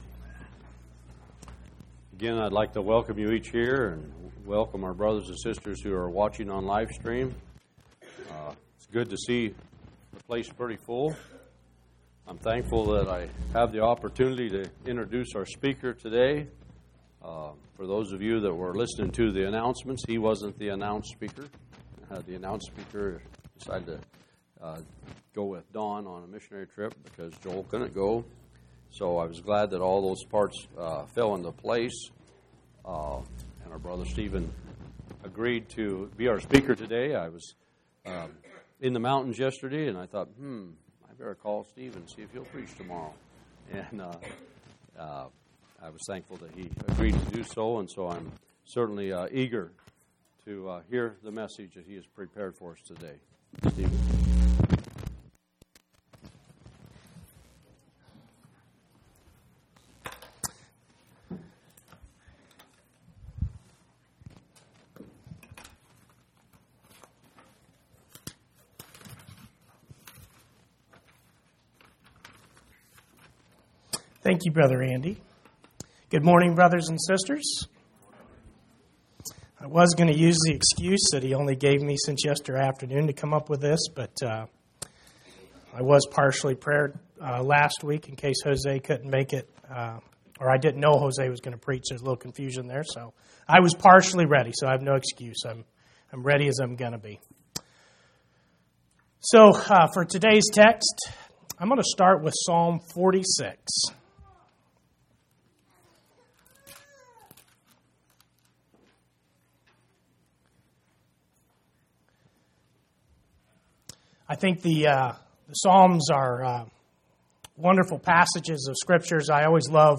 9/11/2016 Location: Phoenix Local Event